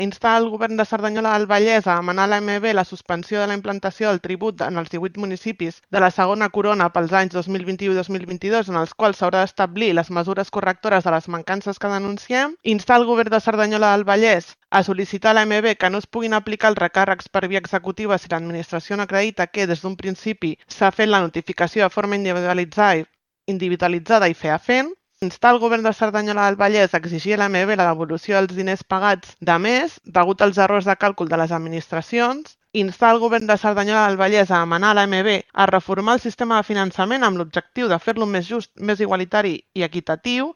Guanyem, Junts per Cerdanyola i PP van presentar una moció conjunt, llegida per la regidora de Guanyem,
Vera Sánchez, regidora Guanyem